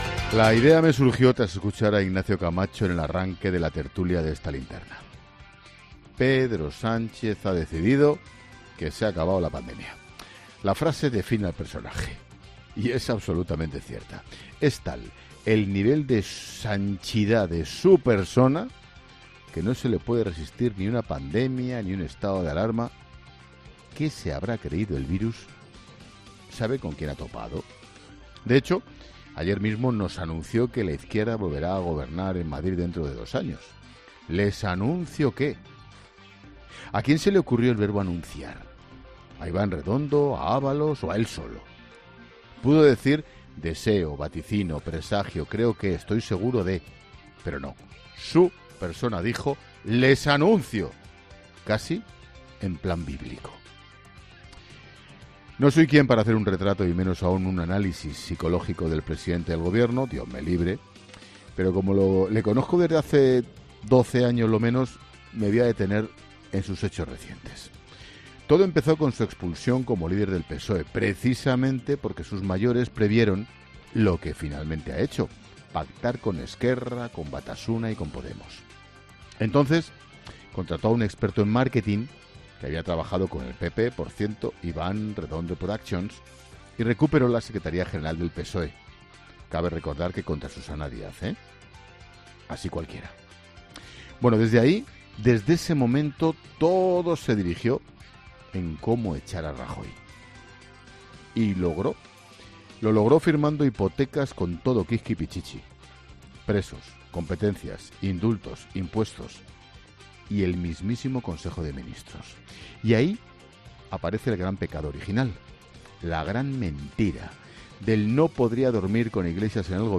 AUDIO: El director de 'La Linterna', Ángel Expósito, critica en su monólogo al presidente del Gobierno